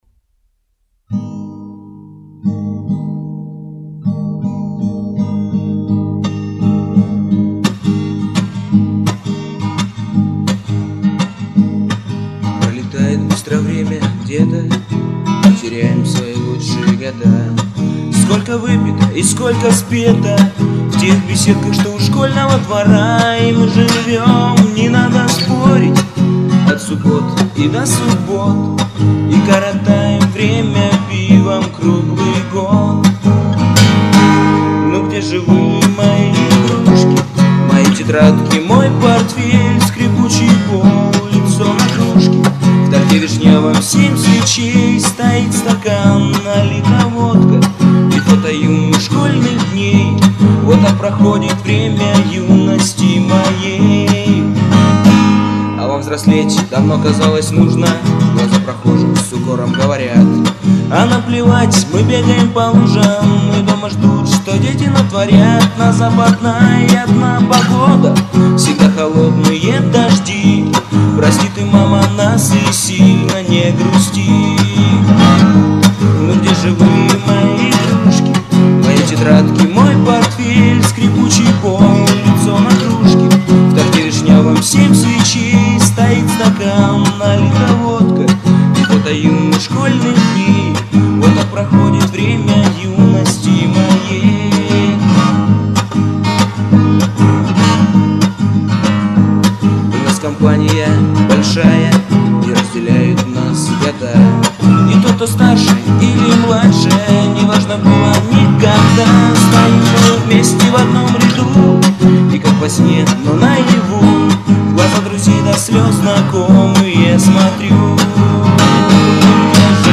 Под_гитару_-_Песня_про_школу
Pod_gitaru___Pesnya_pro_shkolu.mp3